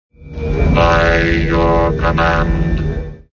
BSG_Centurion-By_Your_Command_03.wav